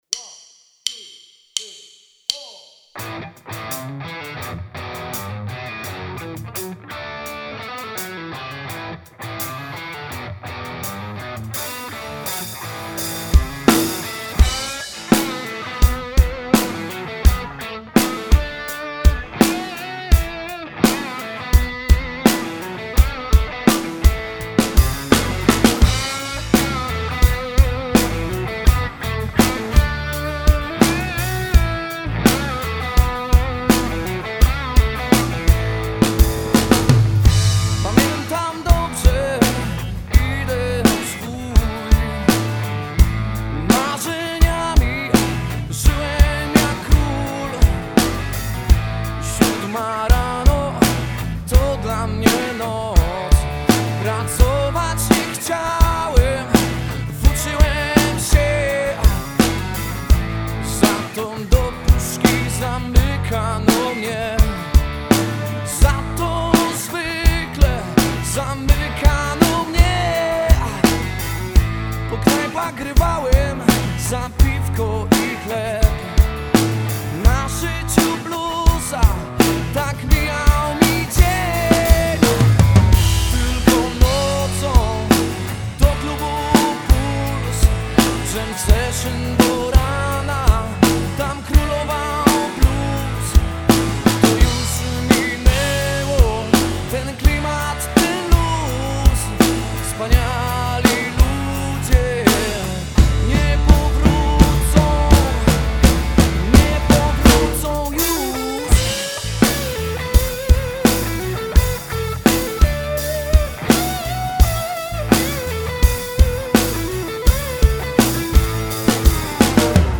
Pobierz ścieżkę perkusji (.mp3)